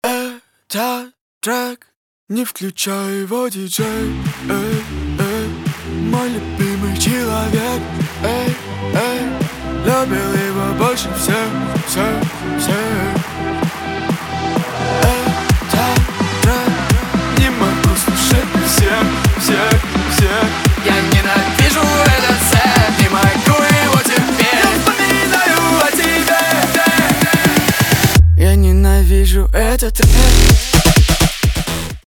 русский рэп , хлопки
битовые , басы , качающие
нарастающие